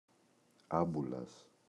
άμπουλας, ο [Ꞌambulas]